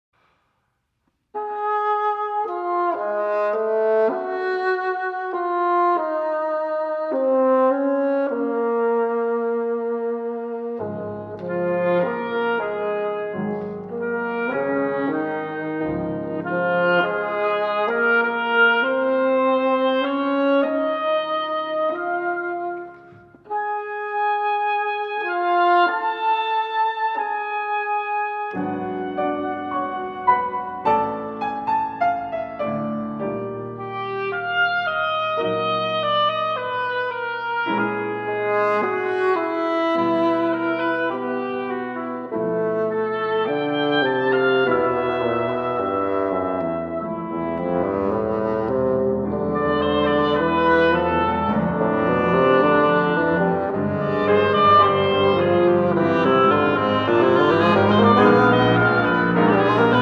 Trio for Oboe, Bassoon, and Piano